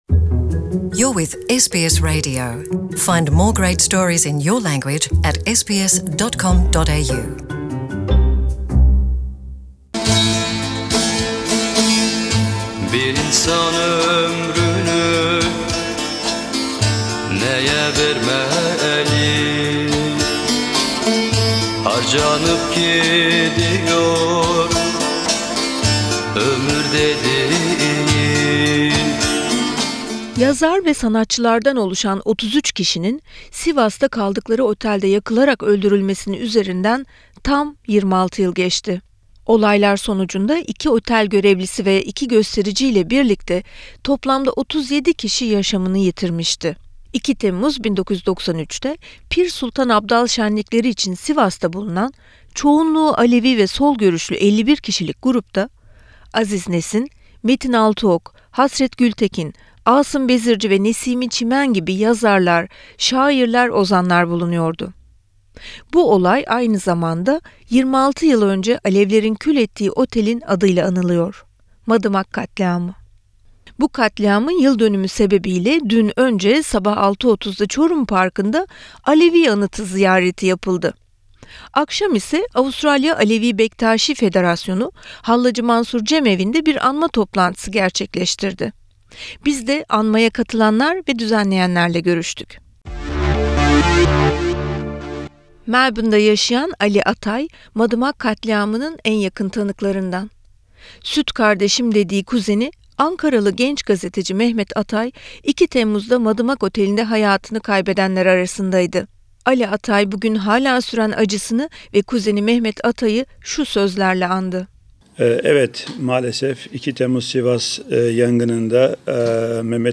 The Alevi community in Australia on Tuesday, July 2, marked the 26th anniversary of the murder of 33 Alevis, including poets and musicians, in an arson attack by a fundamentalist mob in the central Anatolian city of Sivas. 33 Alevis, two employees of the hotel that was torched and two members of the crowd died in the attack, known as the Sivas Massacre. We spoke to some members the local Alevi community during the sombre commemoration ceremony held at the Halac-I Mansur Cemevi in Melbourne.